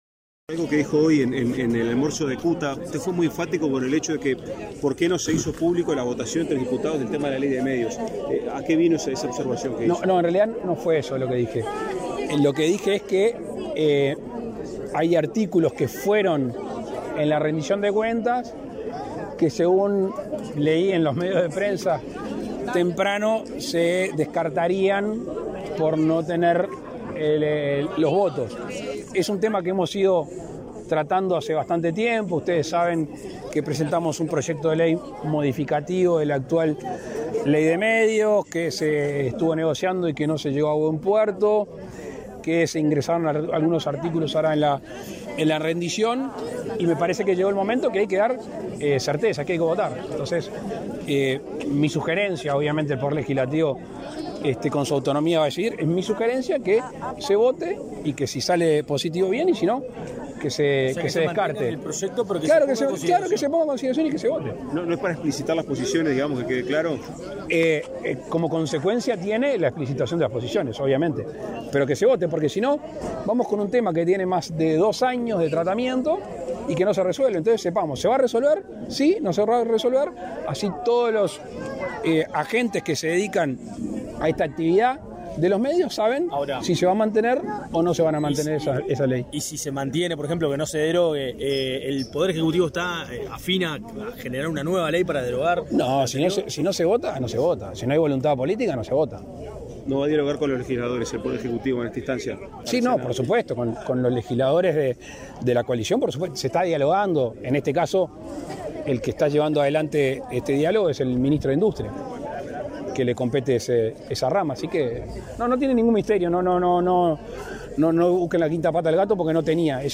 Declaraciones a la prensa del presidente de la República, Luis Lacalle Pou
Tras participar en la inauguración de dos policlínicas en Las Piedras, departamento de Canelones, este 11 de agosto, el presidente Luis Lacalle Pou